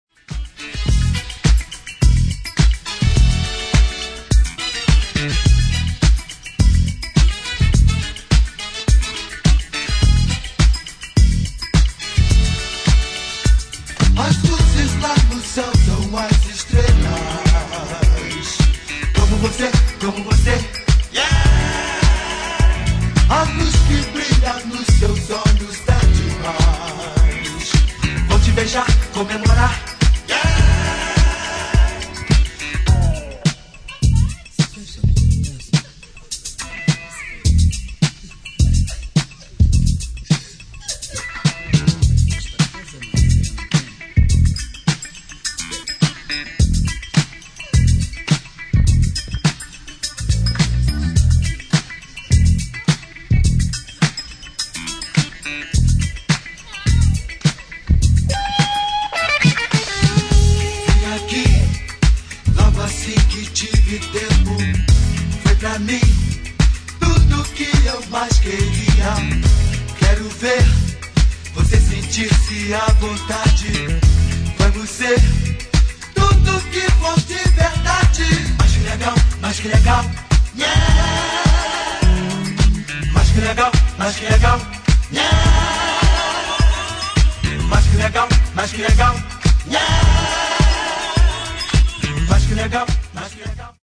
[ DISCO / JAZZ ]